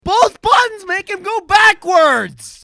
rage gaming angry anger